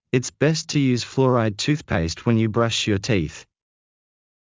ｲｯﾂ ﾍﾞｽﾄ ﾄｩｰ ﾕｰｽﾞ ﾌﾛｰﾗｲﾄﾞ ﾄｩｰｽﾍﾟｰｽﾄ ｳｪﾝ ﾕｰ ﾌﾞﾗｯｼｭ ﾕｱ ﾃｨｰｽ